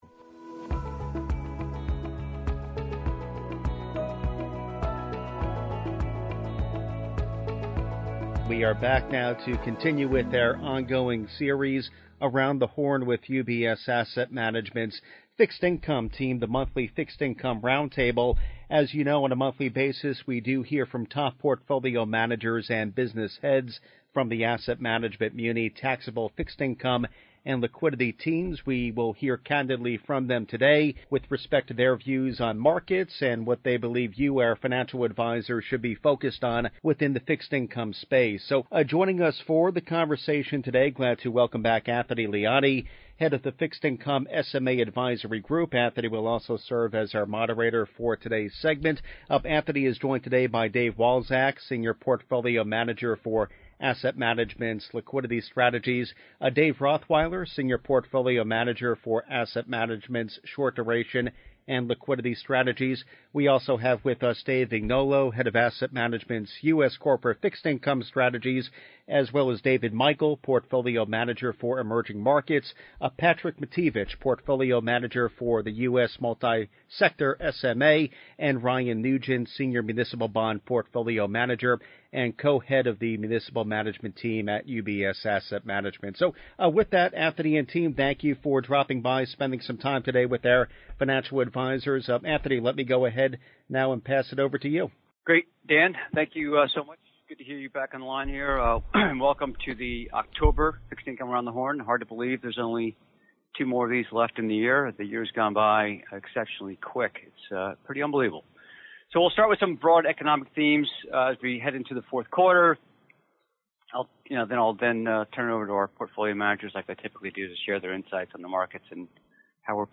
Around the Horn: Monthly Fixed Income Roundtable with UBS Asset Management
UBS On-Air: Market Moves Around the Horn: Monthly Fixed Income Roundtable with UBS Asset Management Play episode October 26 32 mins Bookmarks View Transcript Episode Description Tune in to hear from top portfolio managers and business heads from UBS Asset Management’s Muni, Taxable Fixed Income and Liquidity teams, as they share their views on markets and what they believe you should be focused on within the fixed income space.